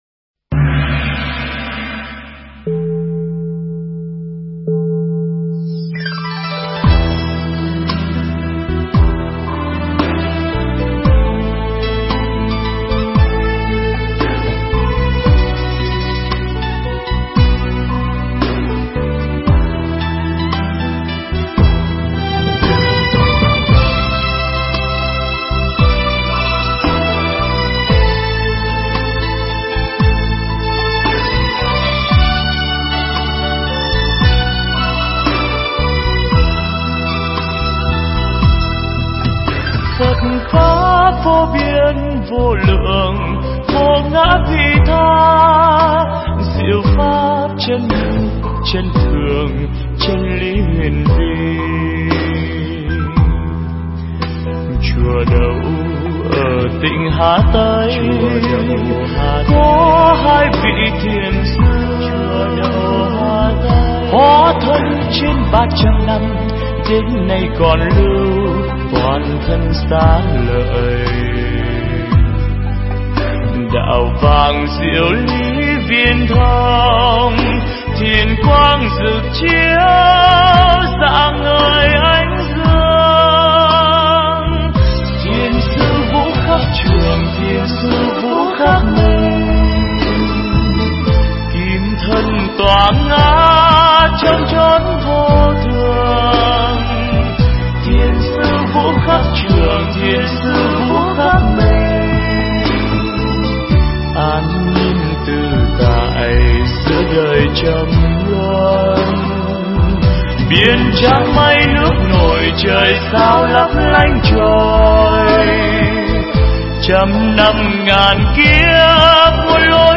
Nghe Mp3 thuyết pháp Thiền Học Ứng Dụng